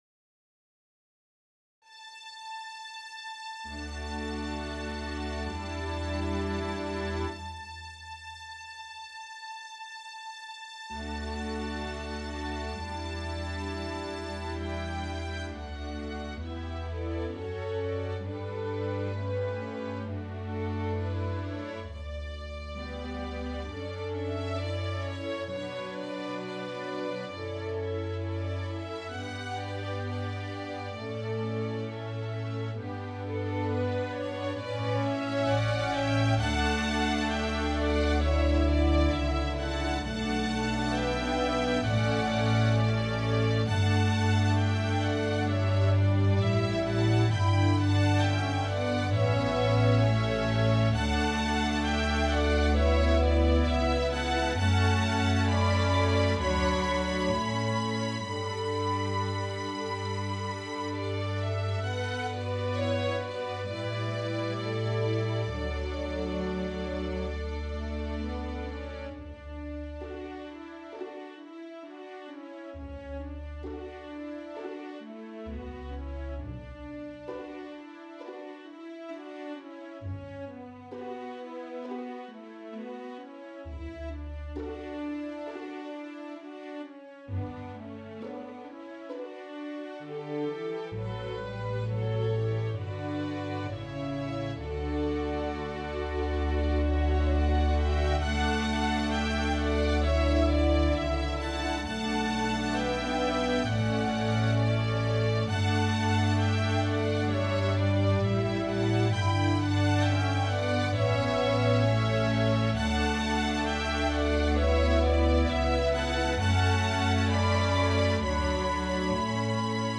The C section introduces trouble, fear, and anxiety.